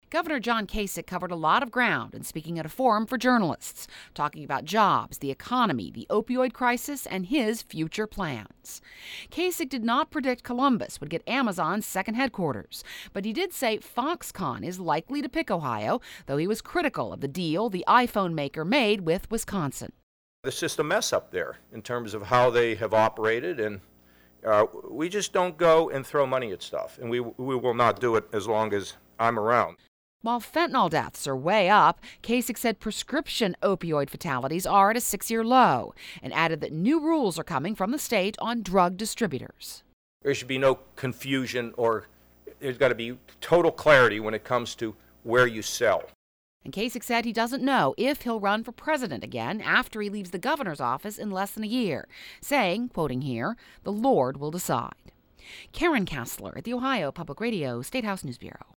Gov. John Kasich takes questions from reporters at the annual Ohio Associated Press Legislative Preview.
Gov. John Kasich covered a lot of ground in speaking at a forum for journalists today – talking about jobs, the economy, the opioid crisis and his future plans.